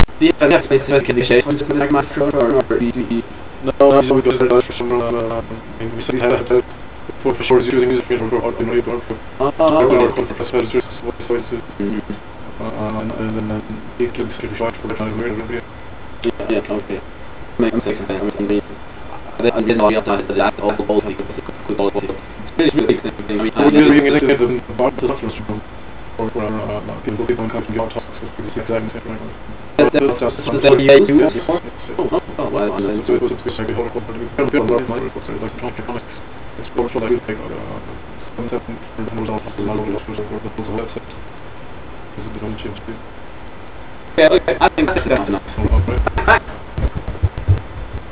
For garbeling the audio, adjacent audio segments of 100ms length were swapped.
two people talking, in office original sound
garbled sound
two_people1_garble.au